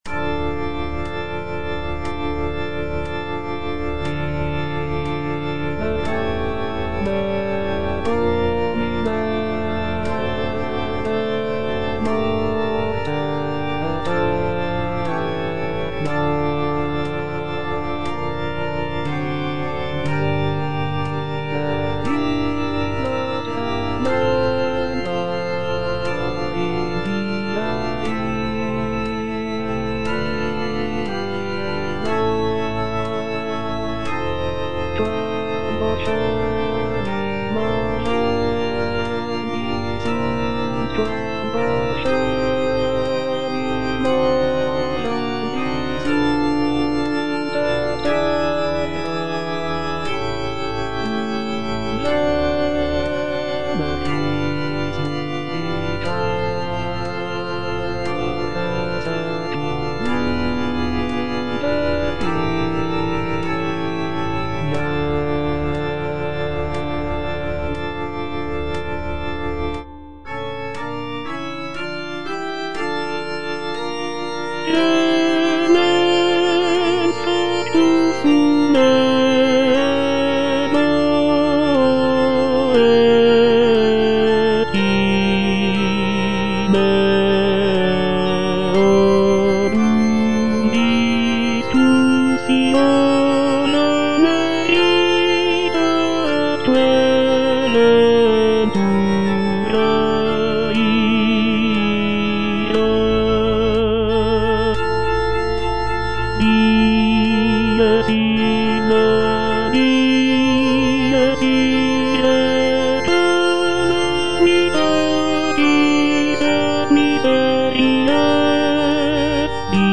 G. FAURÉ - REQUIEM OP.48 (VERSION WITH A SMALLER ORCHESTRA) Libera me (tenor II) (Voice with metronome) Ads stop: